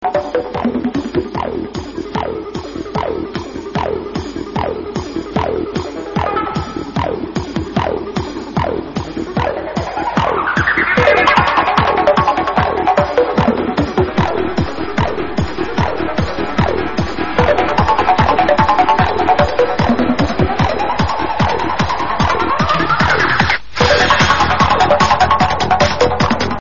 I need ID this track, please (trance&house from 97)